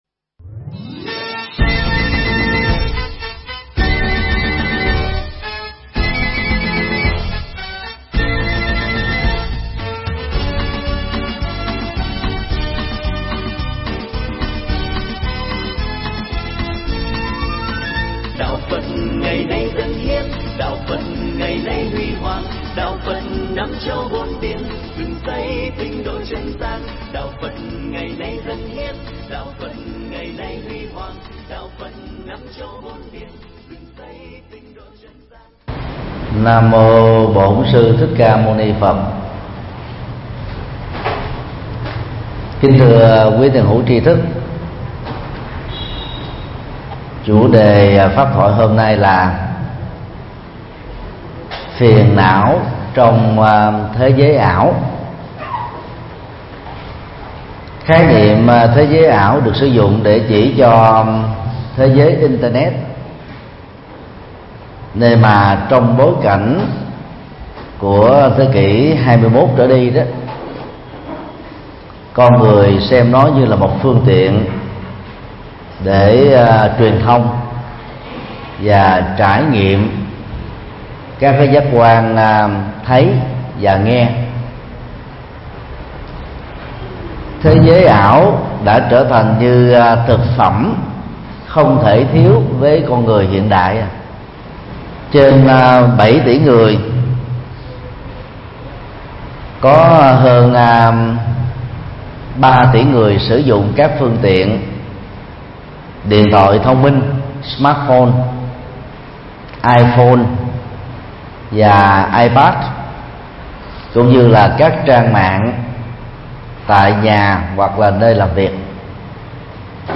Mp3 Thuyết Pháp Phiền não trong thế giới ảo - TT. Thích Nhật Từ Giảng tại Giảng tại chùa Ấn Quang 243 Sư Vạn Hạnh, Phường 9, Quận 10, ngày 19 tháng 4 năm 2015